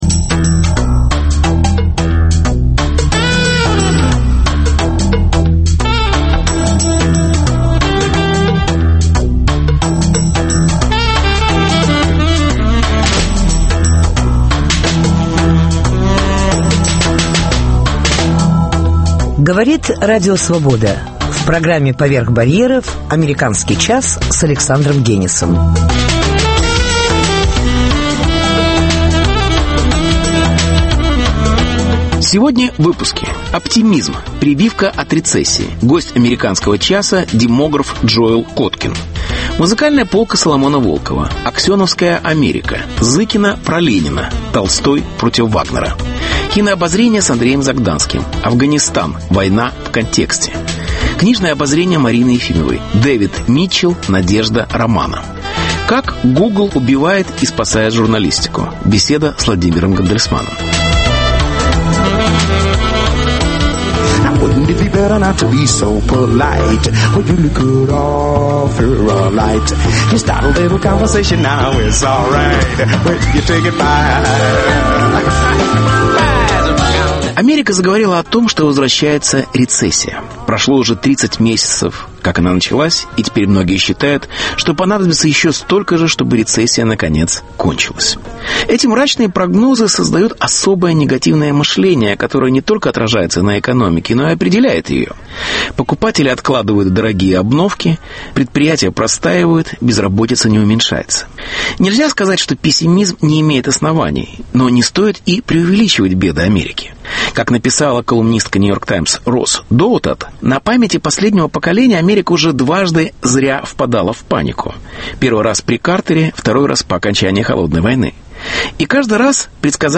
Интервью. Оптимизм: прививка от рецессии.